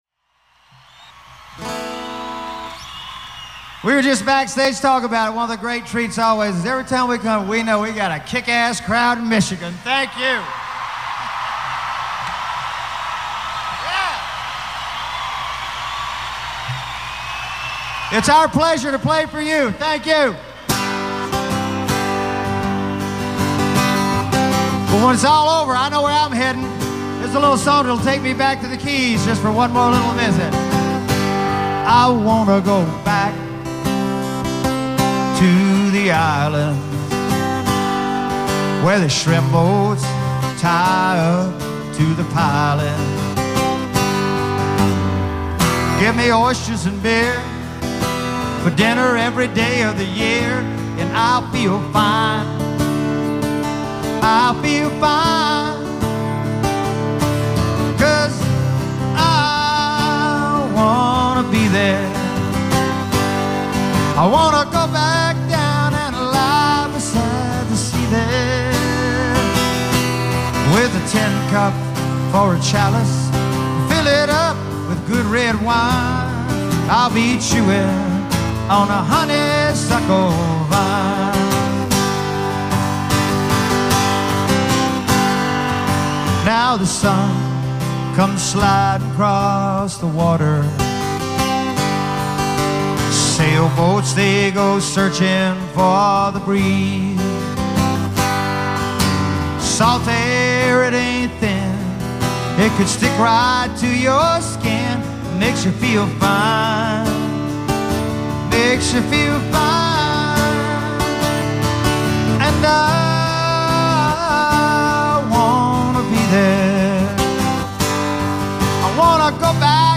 Pine Knob, Clarkston MI